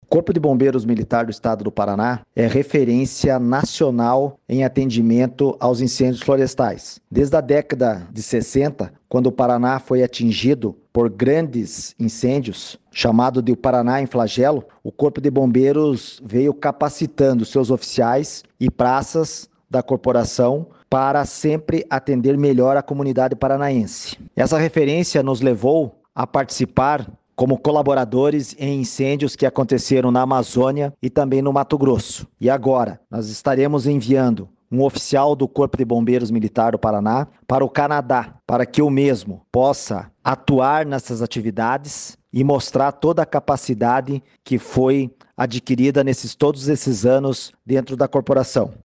Sonora do comandante-geral do Corpo de Bombeiros Militar do Paraná, coronel Manoel Vasco de Figueiredo Junior, sobre o apoio do Paraná ao Canadá no combate aos incêndios florestais